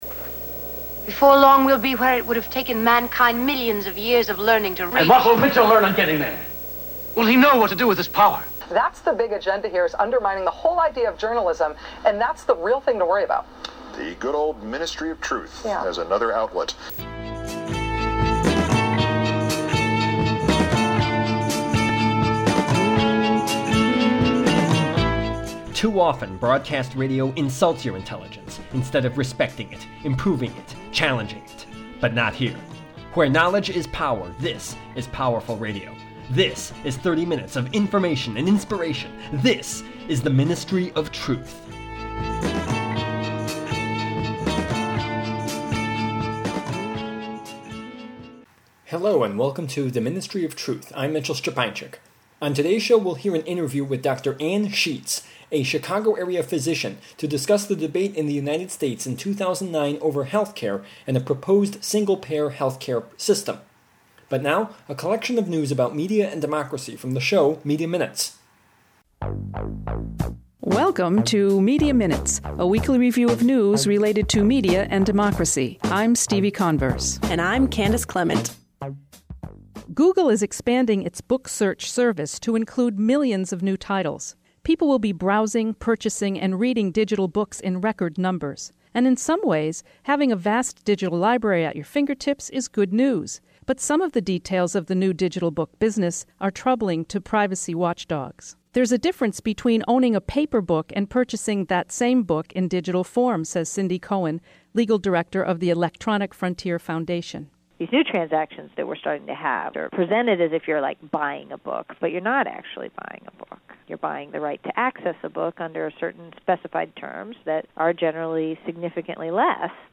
The Ministry of Truth: Interview
Tags: radio